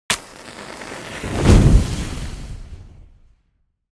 fire_start_burning_to_end2.ogg